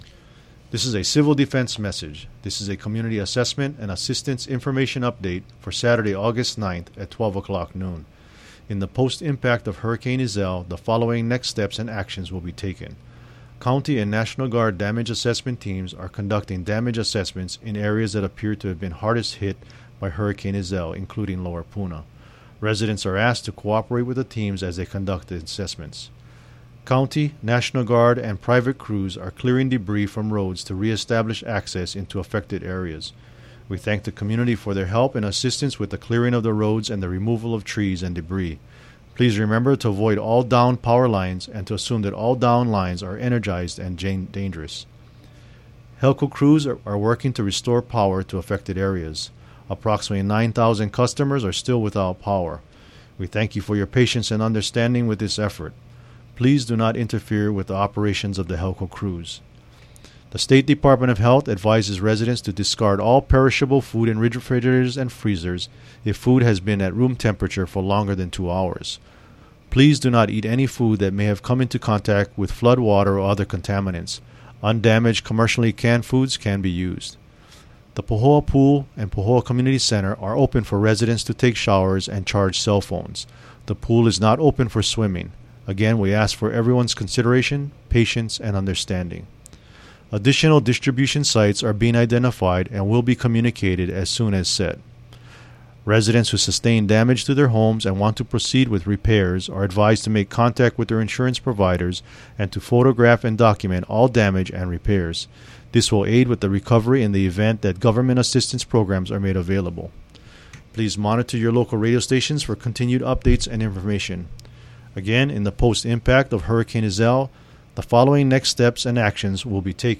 Hawaii County Civil Defense message (Aug. 9 – noon)